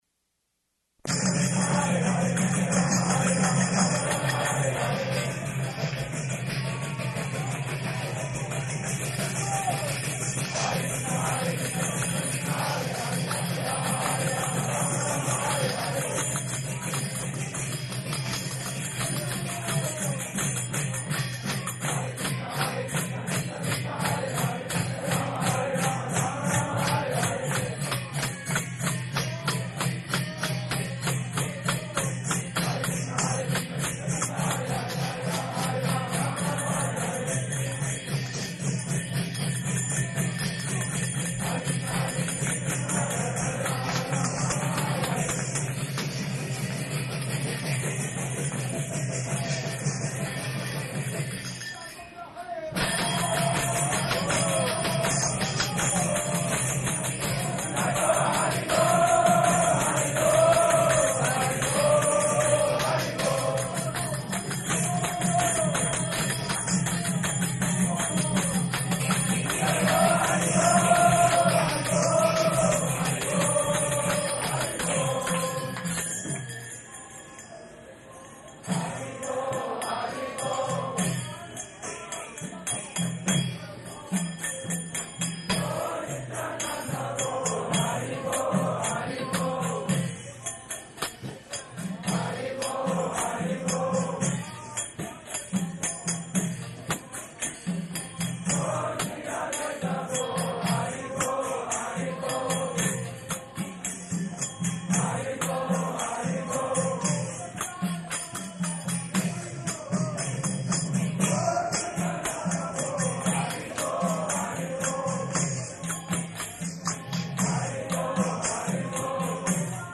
Location: New York
[ kīrtana ]
[devotees repeat] Śrīmad-Bhāgavatam, 2nd Canto, 2nd Chapter, 5th verse.